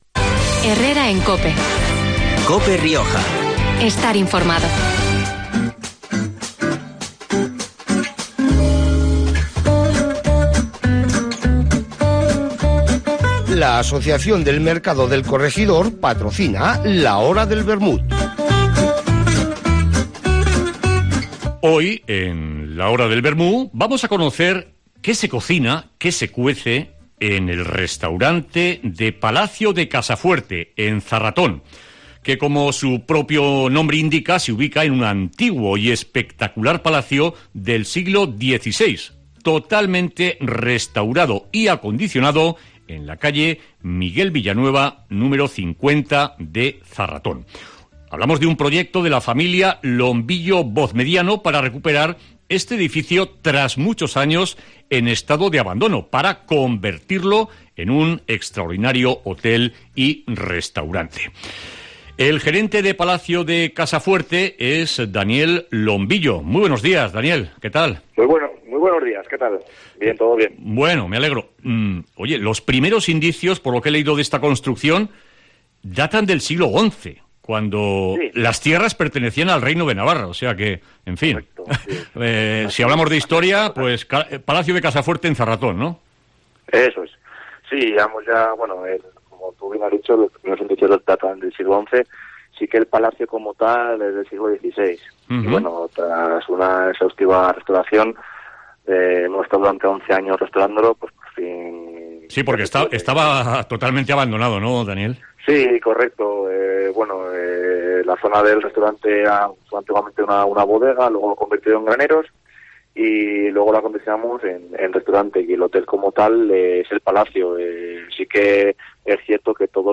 Entrevista en COPE Rioja